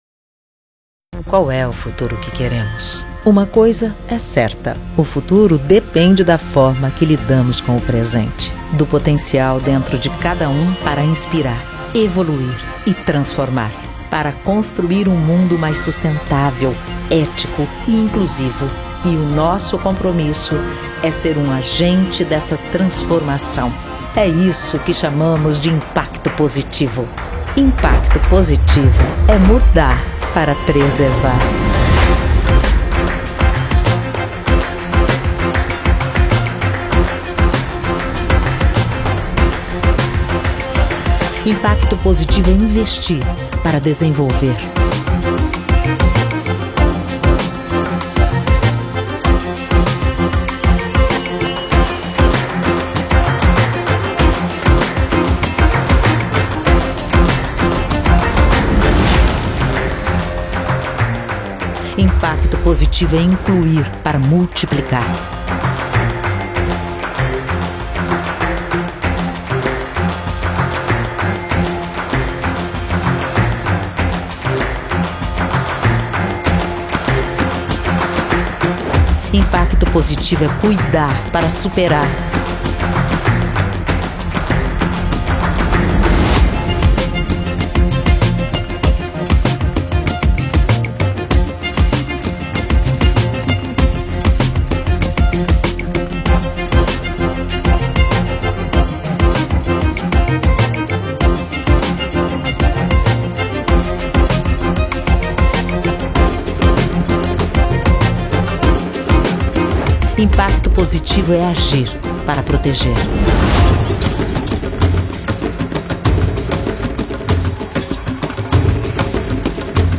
voz forte /Banco Itaú - 2021
Voz Padrão - Grave 02:35
Owns an at home recording studio.